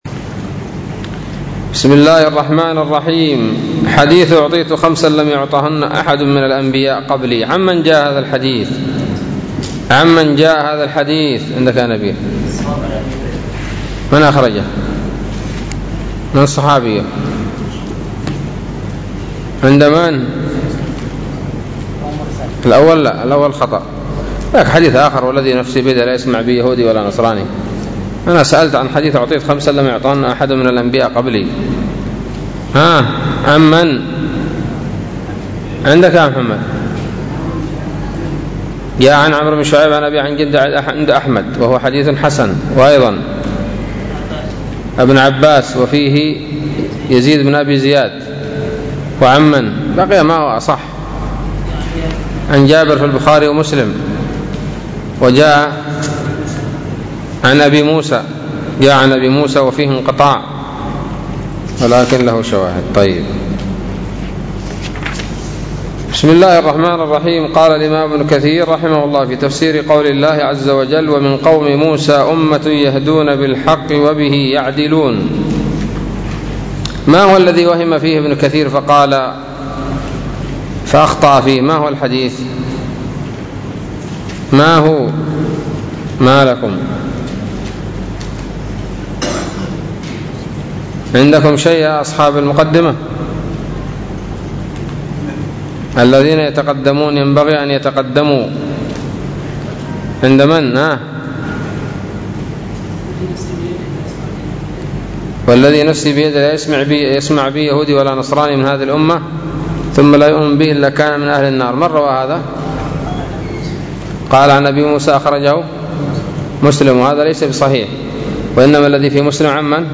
007 سورة الأعراف الدروس العلمية تفسير ابن كثير دروس التفسير